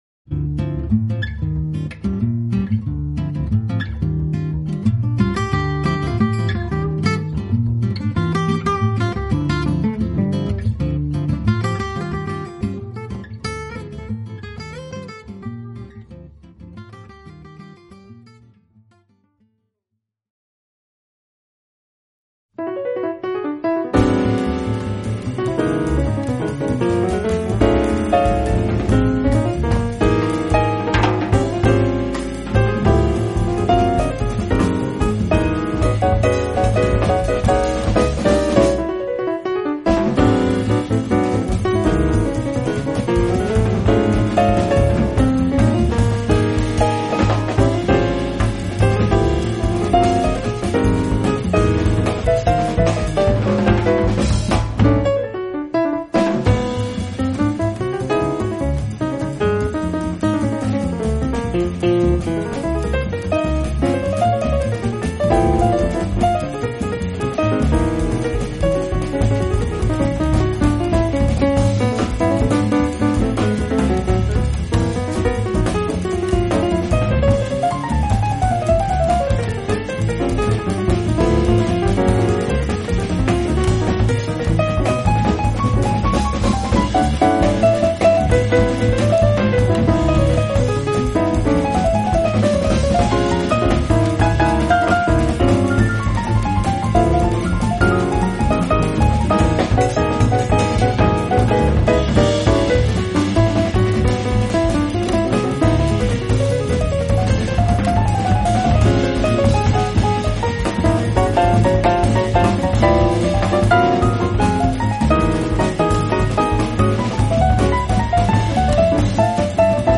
Japanese jazz artists